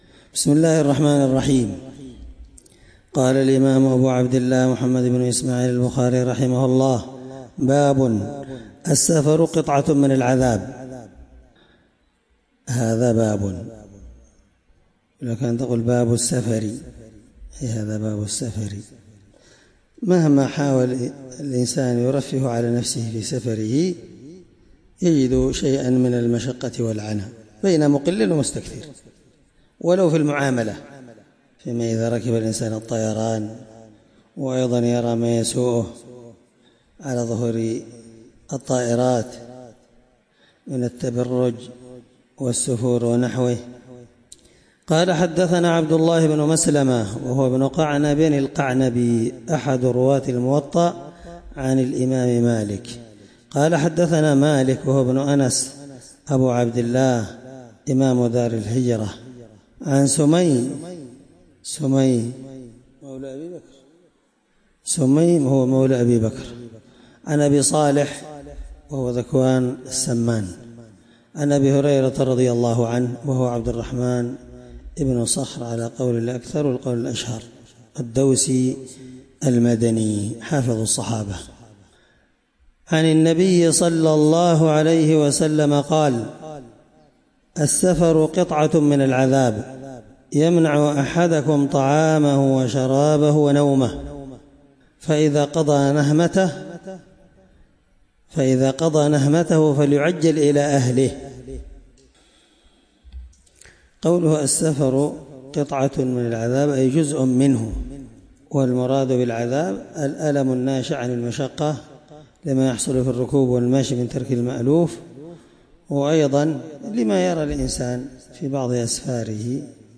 الدرس 14من شرح كتاب العمرة حديث رقم(1804)من صحيح البخاري